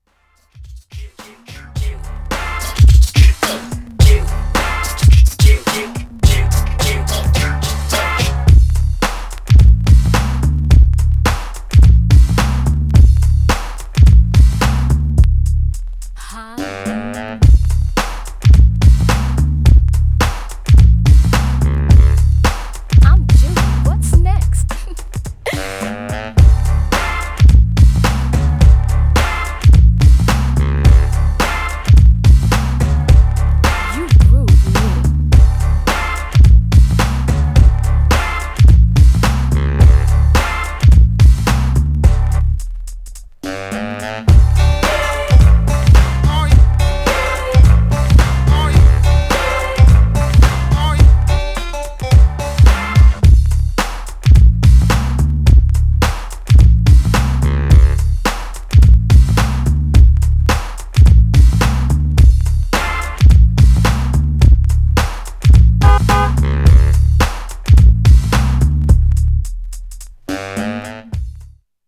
Styl: Hip Hop, Breaks/Breakbeat